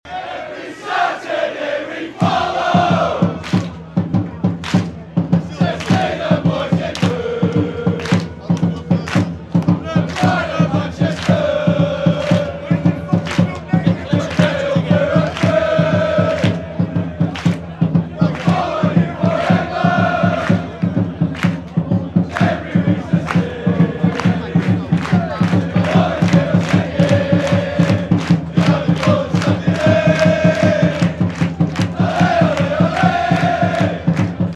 part 65 | rochdale chant sound effects free download